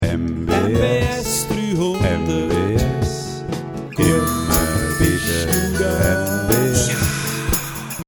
MB S300 - Der Klingelton
Die Klingeltöne zum MB S300 und zum QP